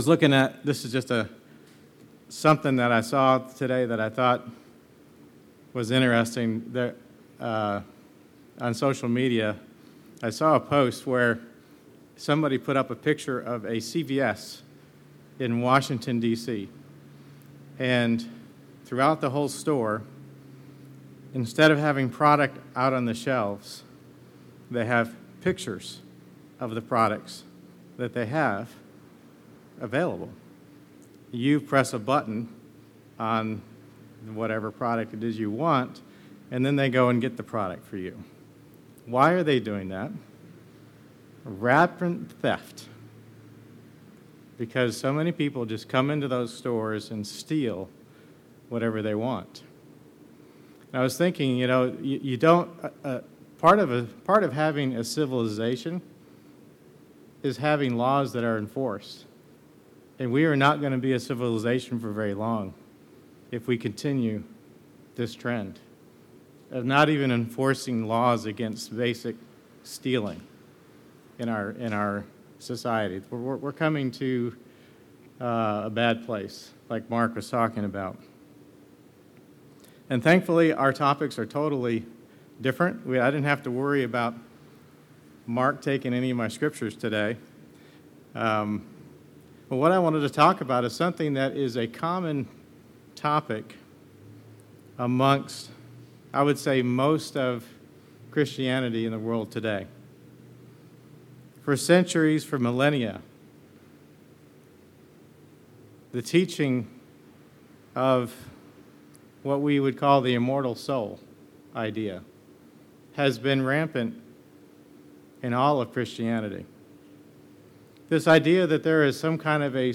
A Lesson on the teachings of the Immortal Soul. If we have immortality, why do we need it?